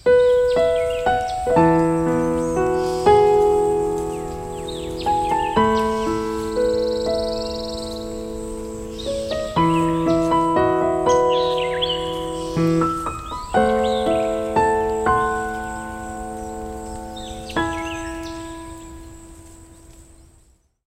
weather_alarm_sun2.ogg